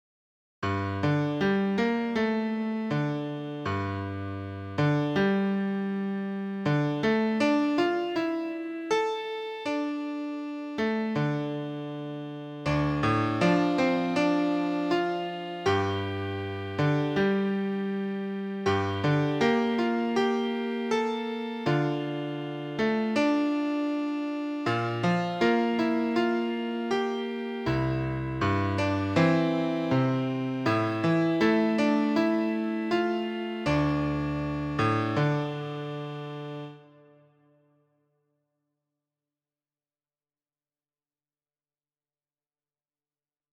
This is a collection of Jewish tunes that I grew up with.